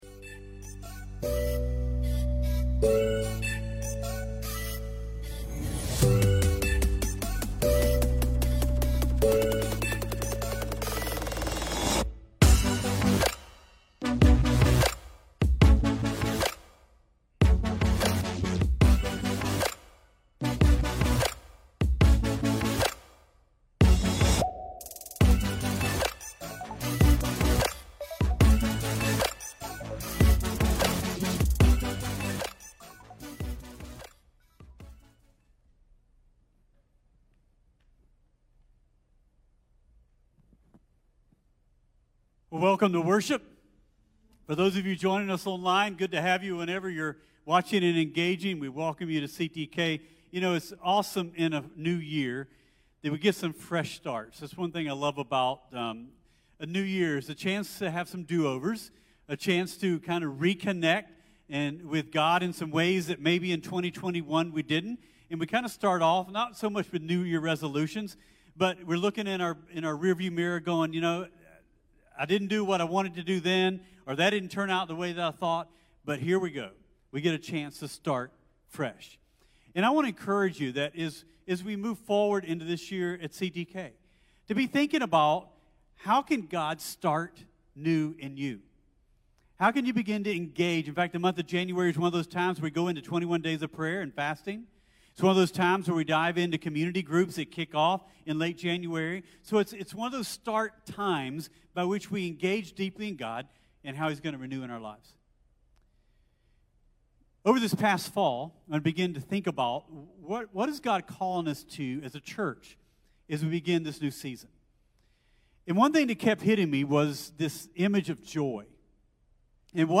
CTK-Sermon-1-1-1.mp3